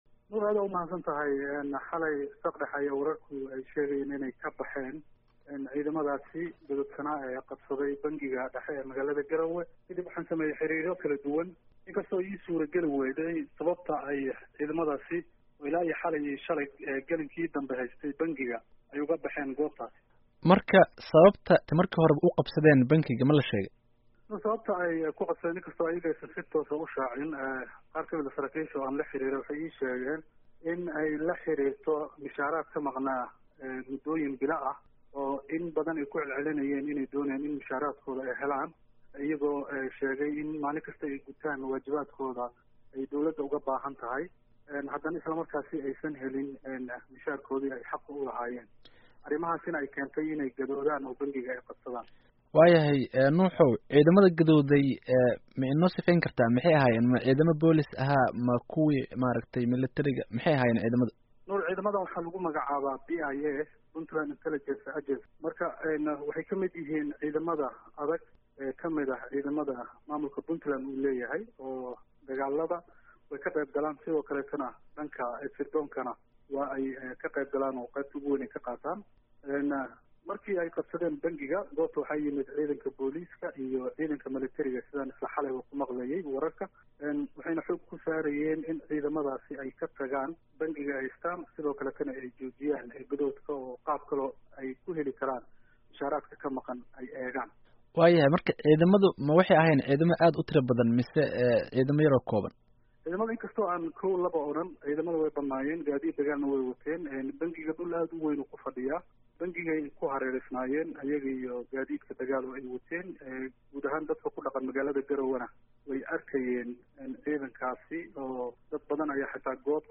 Wareysiga